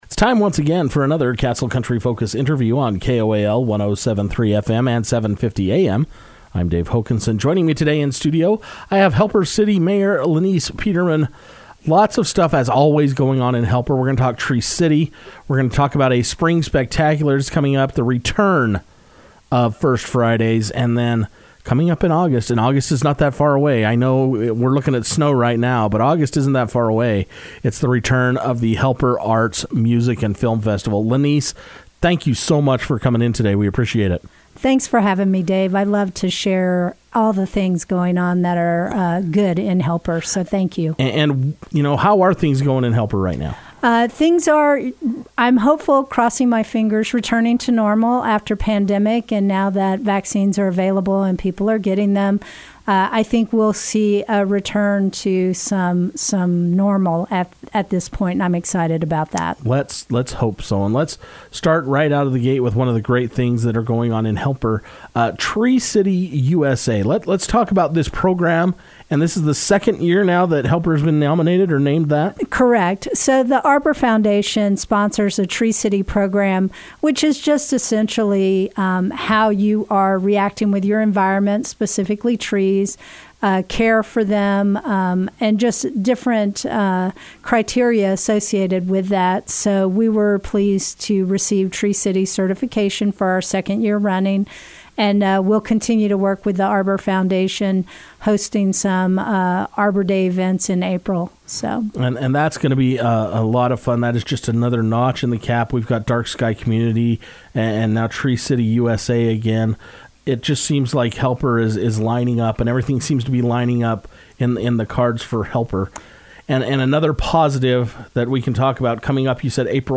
There is always something fun and exciting taking place in Helper so Castle Country Radio caught up with Mayor Lenise Peterman to find out what is happening. She was able to talk about Helper being named Tree City U.S.A, the upcoming Spring Spectacular, and the return of First Friday events.